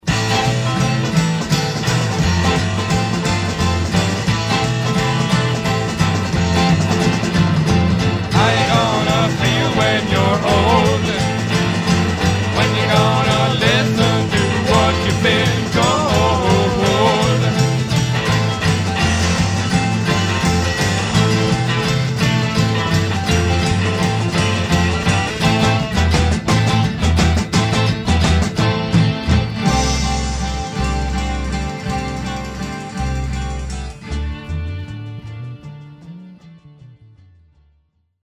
12-string guitars, slide guitar, harmonica, lead vocals
keyboards, lead guitar, vocals
bass, vocals
drums, percussion